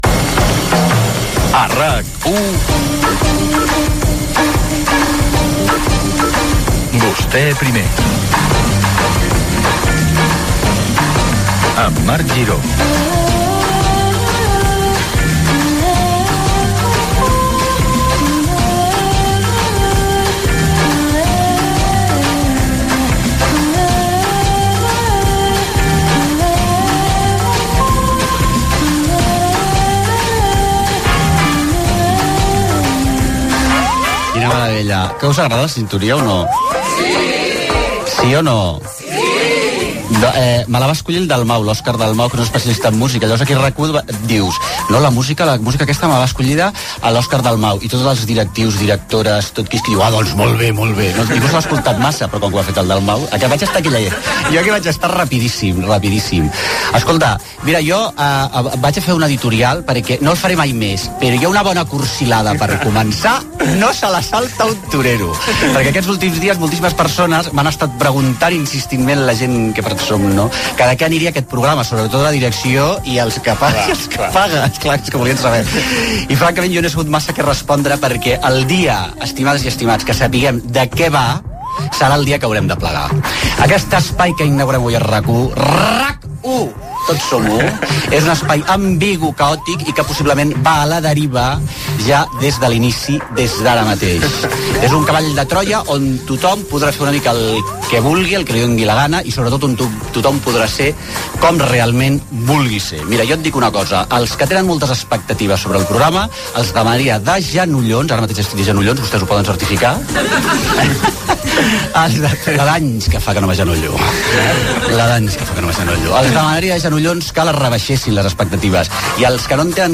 19bcada6b2f43fcc4e0dd2c5f0e13b13632a00f7.mp3 Títol RAC 1 Emissora RAC 1 Barcelona Cadena RAC Titularitat Privada nacional Nom programa Vostè primer Descripció Primera edició del programa. Careta del programa, comentari sobre la sintonia, editorial sobre el qu es farà al programa, indicatiu del programa
conversa amb el públic que està a l'estudi.
Entreteniment